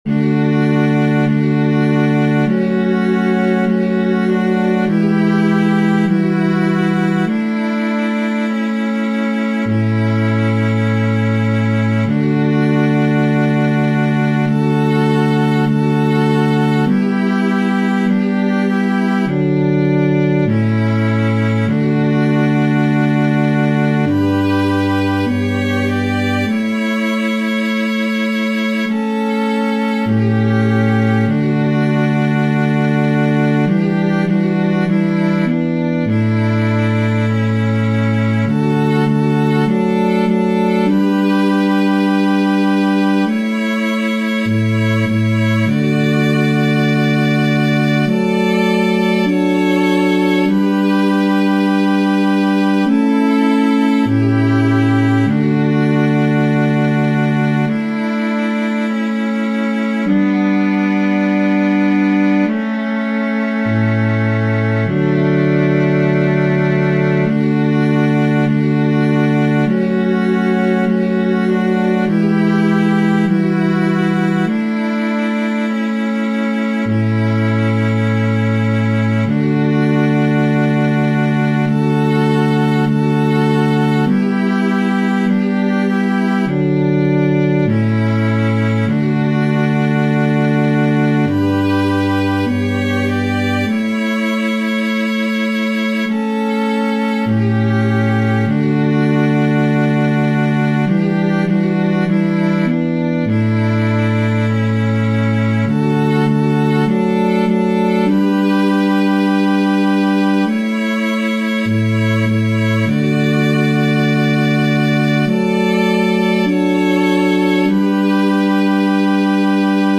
Chants de Méditation Téléchargé par